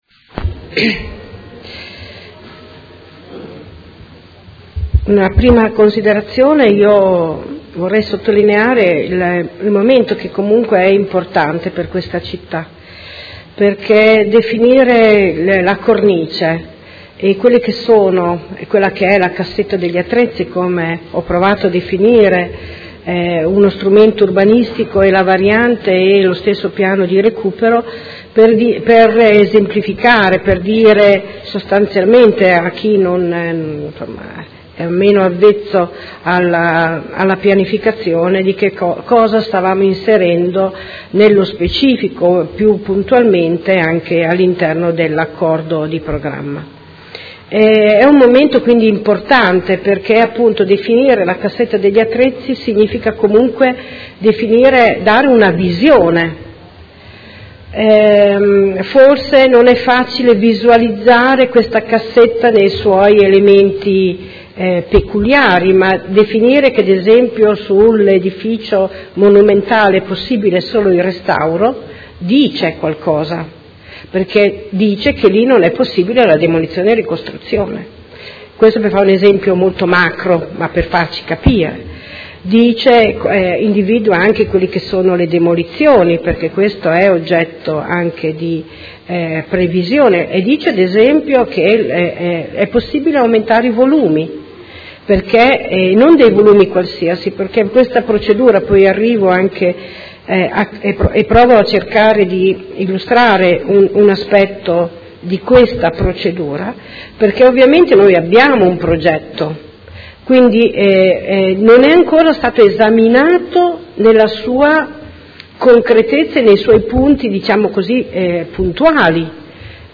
Anna Maria Vandelli — Sito Audio Consiglio Comunale
Seduta del 04/06/2018.